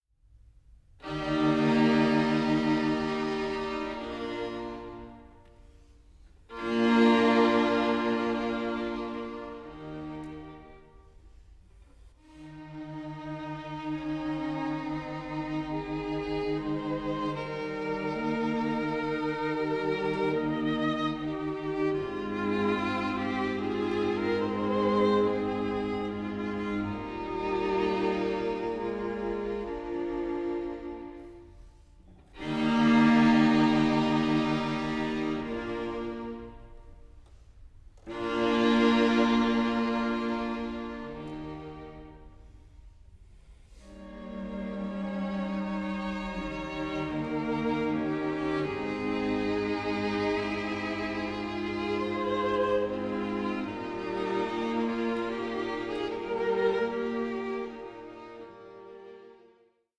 remastered recording